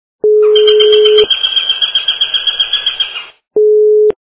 » Звуки » Природа животные » Птичка - Королек
При прослушивании Птичка - Королек качество понижено и присутствуют гудки.
Звук Птичка - Королек